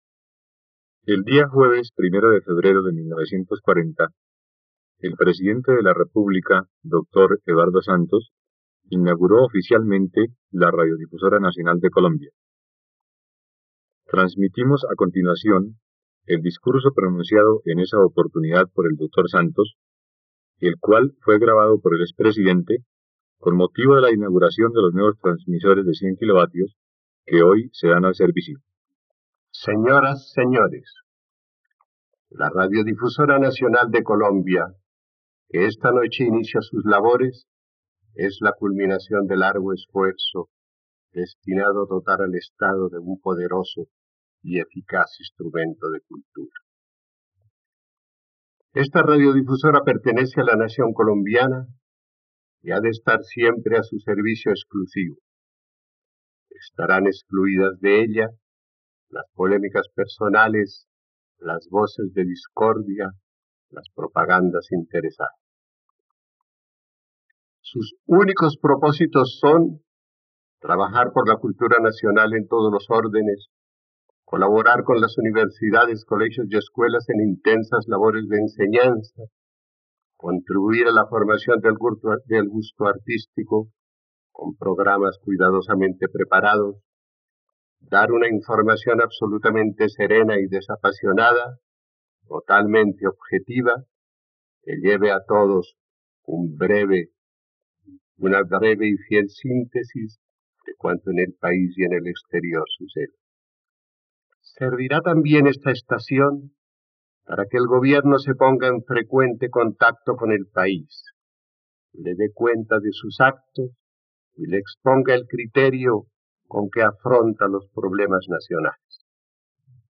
..Escucha ahora el discurso de Eduardo Santos durante la inauguración de la Radiodifusora Nacional de Colombia, el 1° de febrero de 1940, en RTVCPlay.